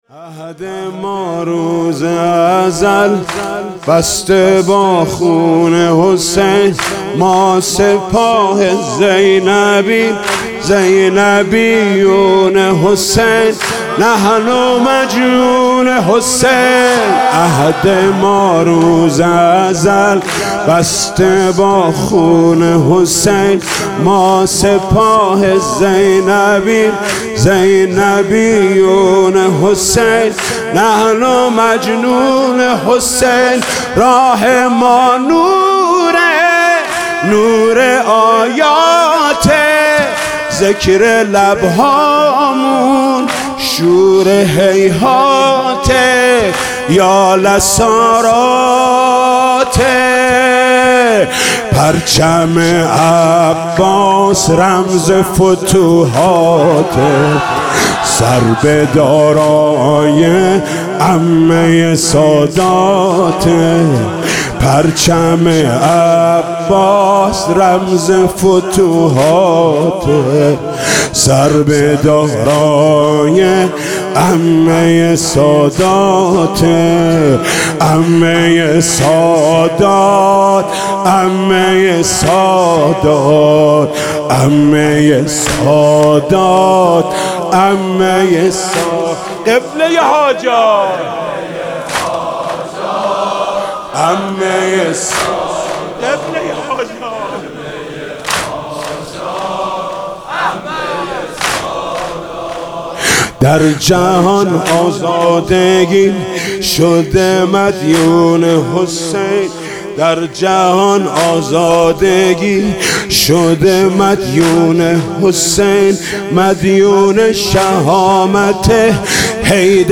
واحد: عهد ما روز ازل بسته با خون حسین